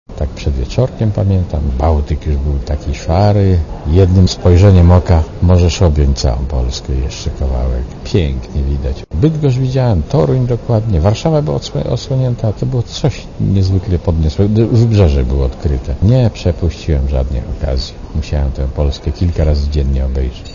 Mówi Mirosław Hermaszewski (80Kb)